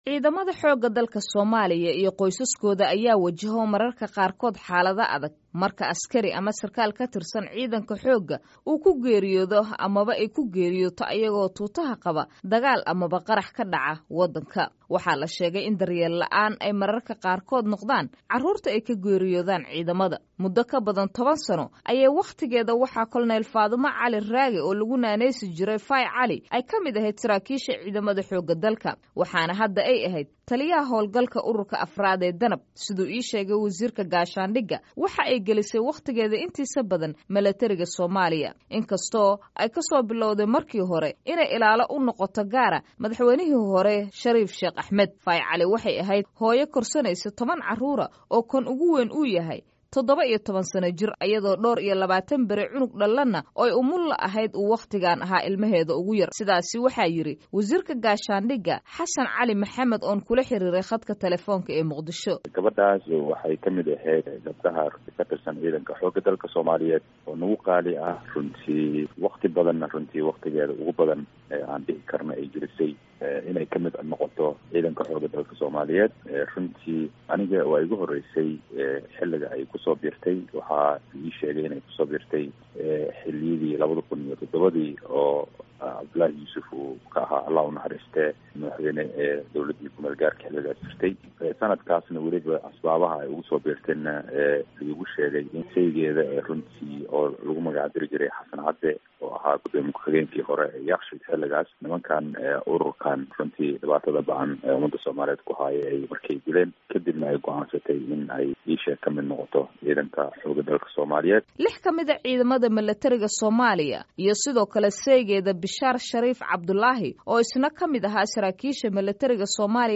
Warbixin gaar ah